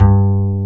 Index of /90_sSampleCDs/Roland L-CDX-01/BS _Jazz Bass/BS _Acoustic Bs